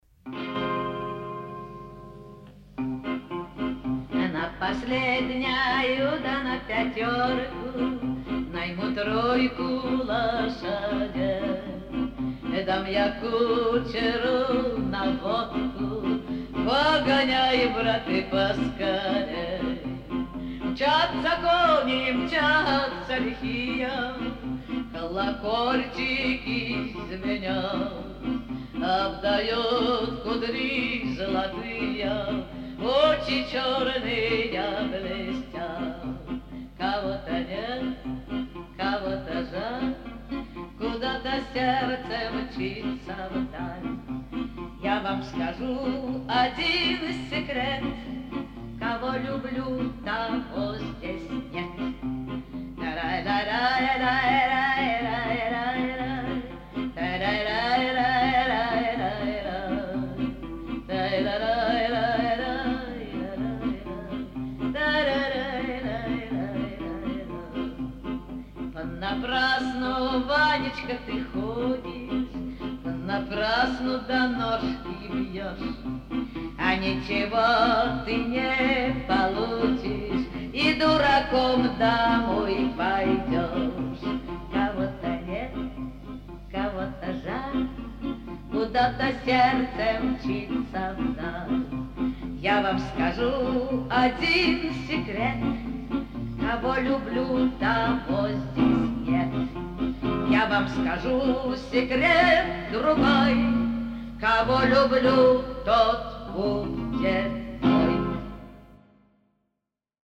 Папка у меня поименована "1977", но вряд ли это год издания этой песни - качество скорее тянет на середину 60х.
Из сети узнал, что певица - русская, эмигрировавшая в Сербию после революции.
Качество - 256, довольно среднее.